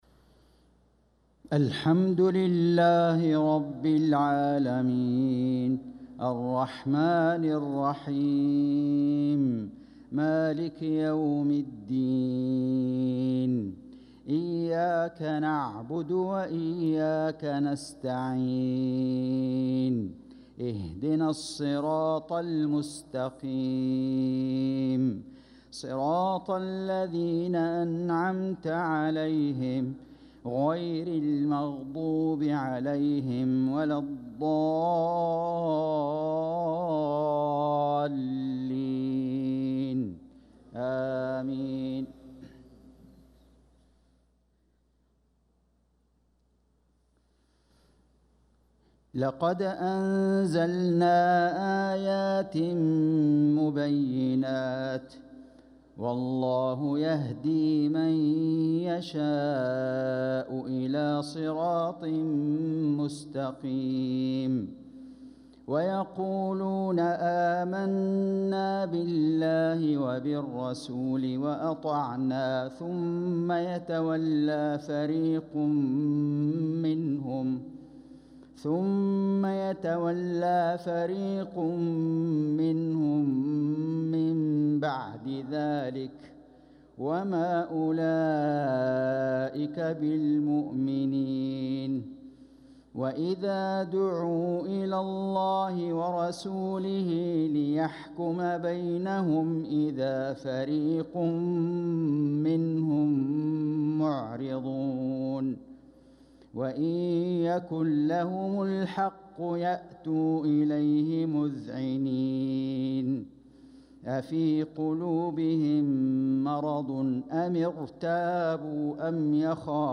صلاة العشاء للقارئ فيصل غزاوي 8 ربيع الأول 1446 هـ